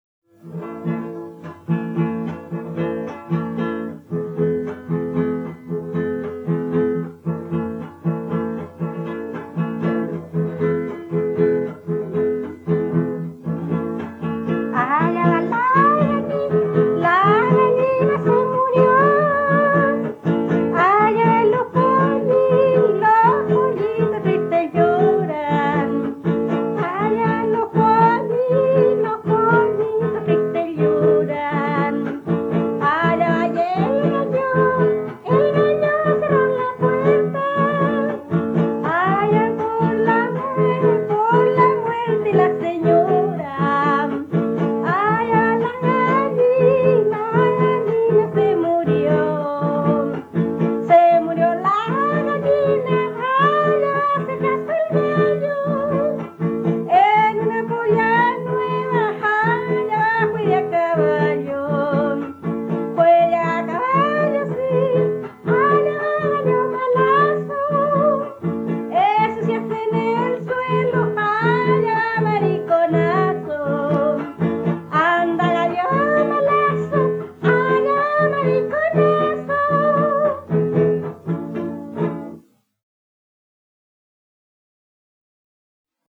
Cueca
Música tradicional
Folklore